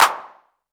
normal-hitclap.wav